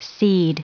Prononciation du mot cede en anglais (fichier audio)
Prononciation du mot : cede